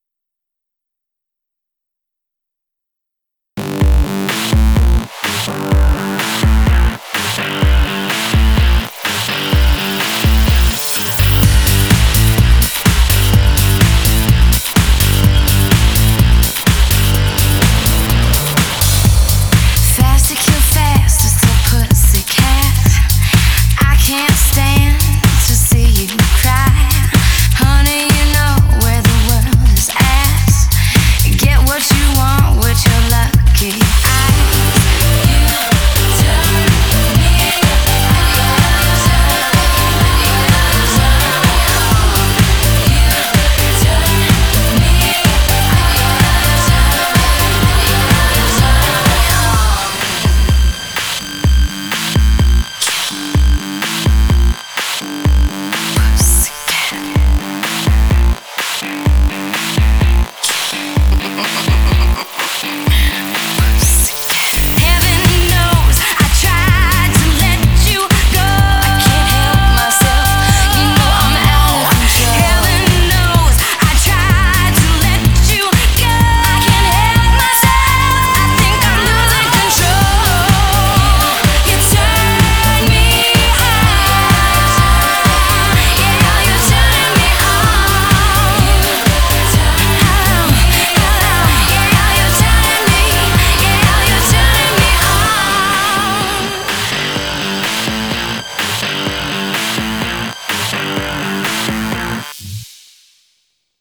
BPM126